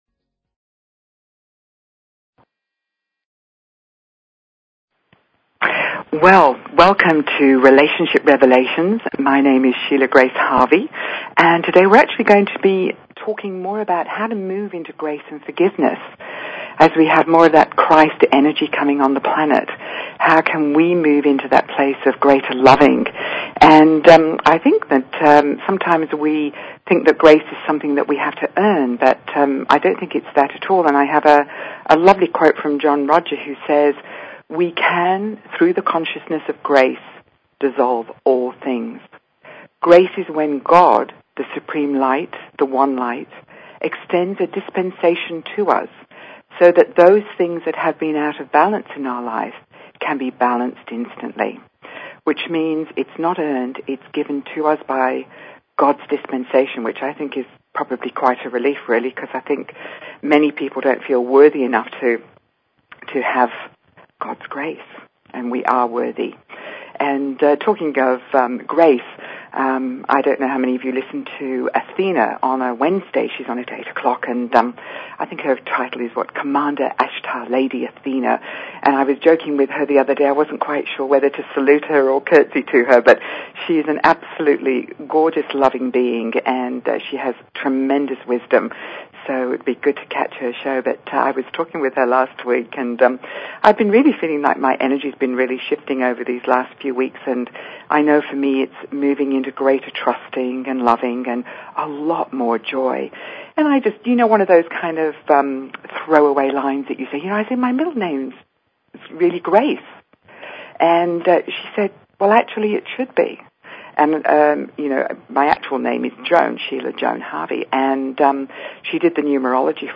Talk Show Episode, Audio Podcast, Relationship_Revelations and Courtesy of BBS Radio on , show guests , about , categorized as